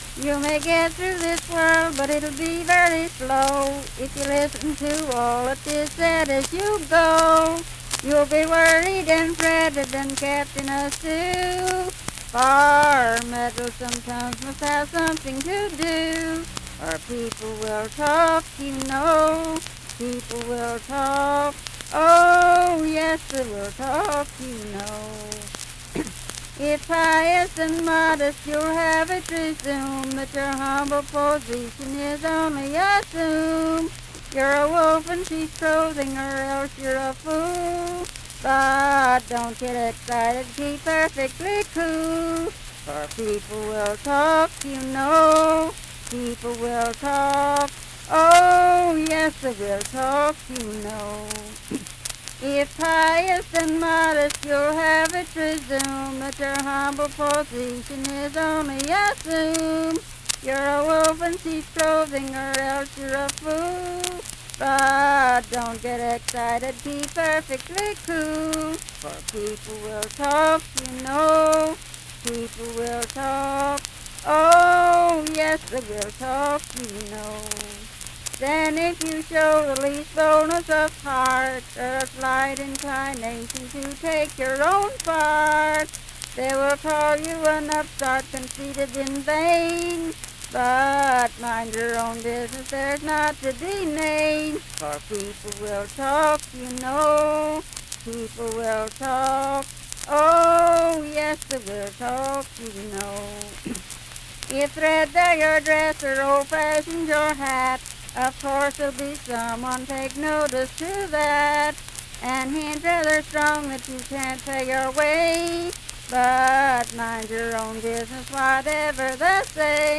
Unaccompanied vocal music
Performed in Daybrook, Monongalia County, WV.
Voice (sung)